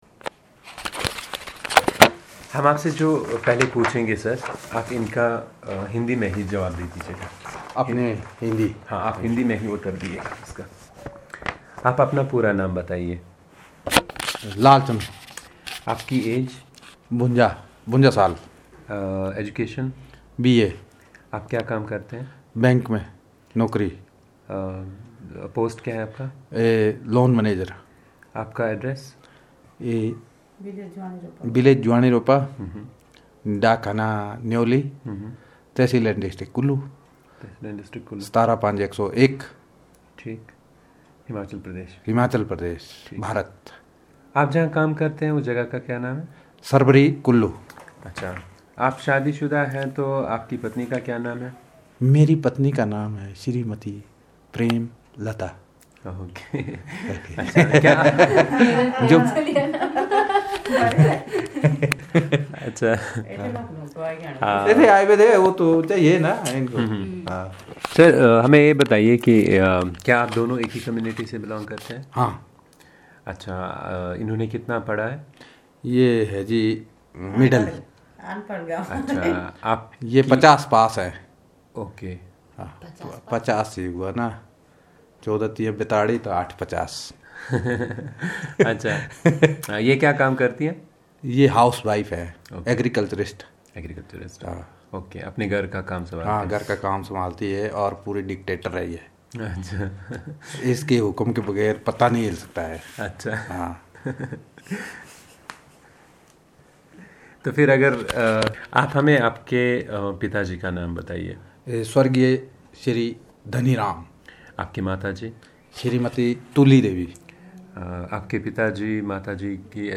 Personal narrative on personal and social information in Chinali